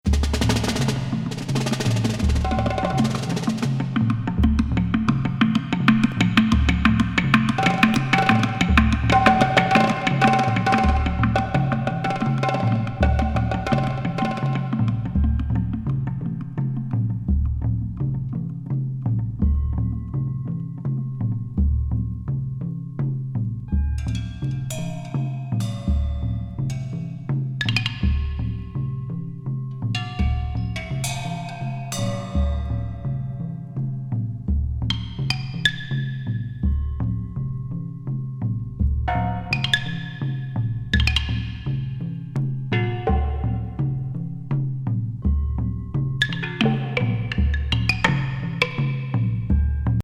地獄エキゾチック～森林ミニマル～メディテーションなジャズドラムまで、
濃厚すぎる音世界！